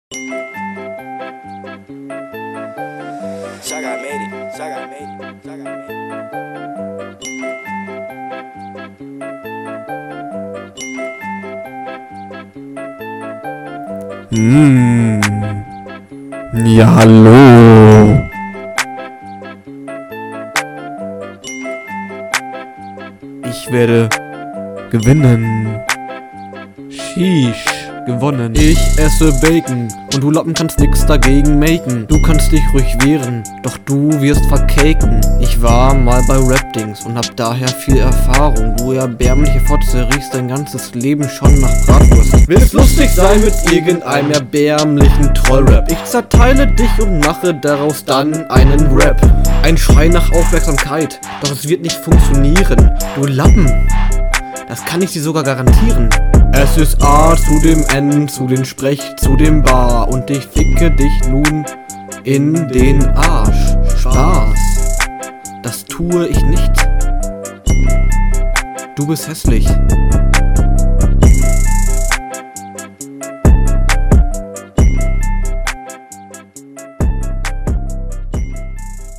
Das ist aufjedenfall wenigstens gerappt.
Dieser Beat echt nice bei 0:17 hats mir meine Kopfhörer weg geschlagen als tipp misch …